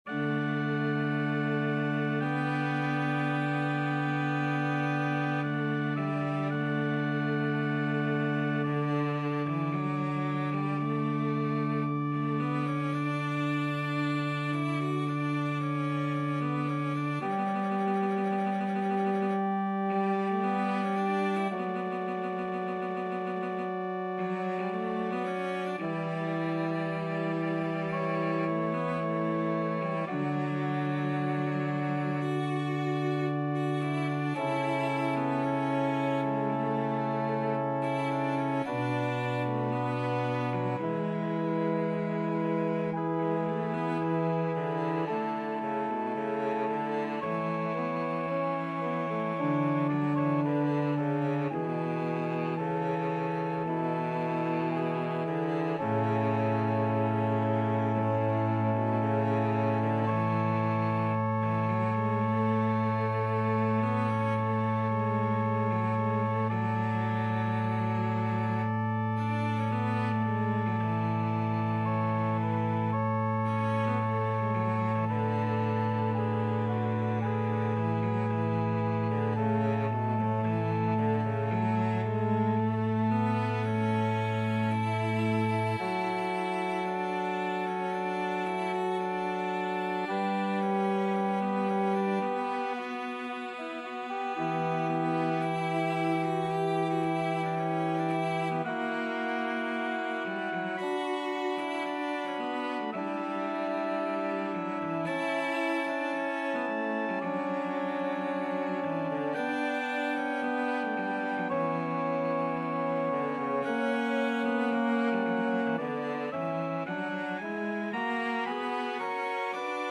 Cello
4/4 (View more 4/4 Music)
=56 Adagio
D major (Sounding Pitch) (View more D major Music for Cello )
Classical (View more Classical Cello Music)